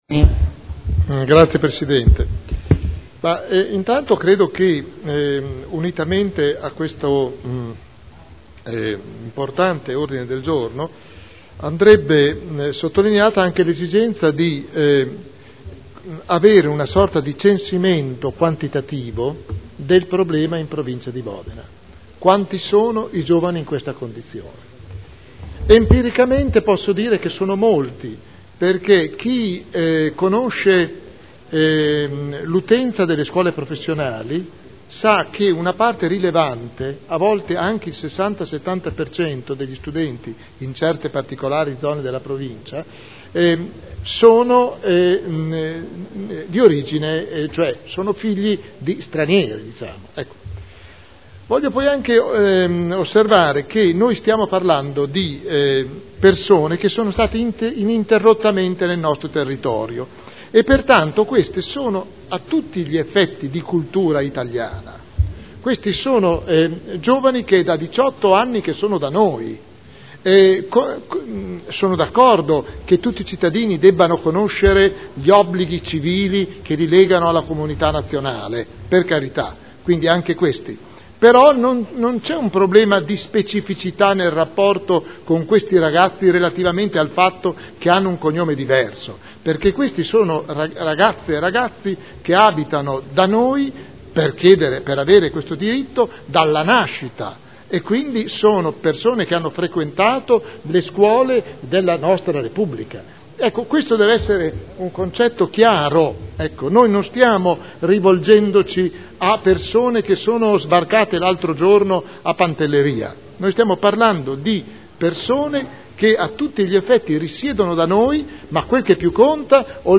Seduta del 16 aprile. Mozione presentata dai consiglieri Prampolini, Artioli, Trande, Garagnani, Goldoni, Pini, Glorioso, Gorrieri, Sala, Guerzoni, Rocco, Codeluppi, Cotrino, Campioli, Cornia, Morini, Rimini, Rossi F., Dori (P.D.) avente per oggetto: “Fratelli in Italia” Dichiarazioni di voto